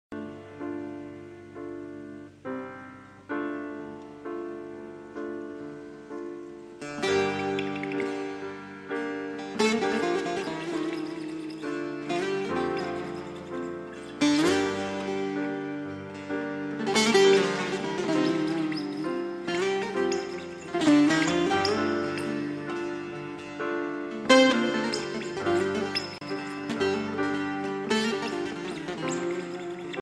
زنگ عربی